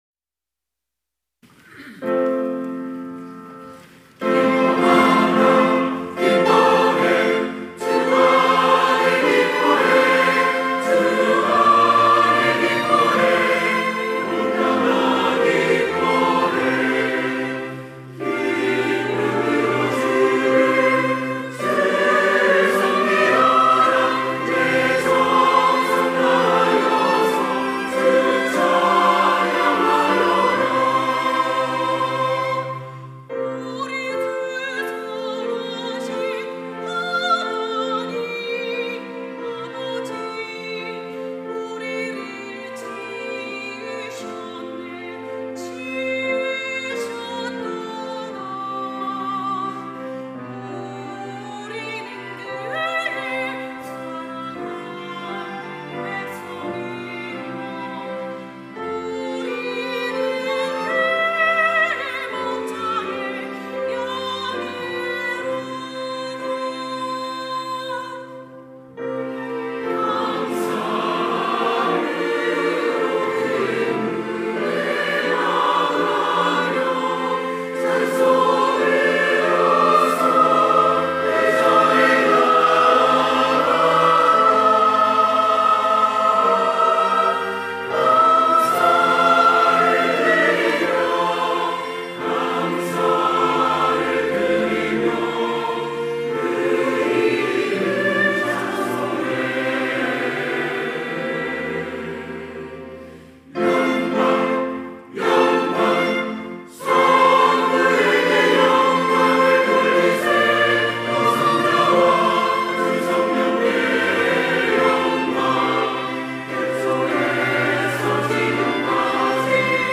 호산나(주일3부) - 주 안에서 기뻐해
찬양대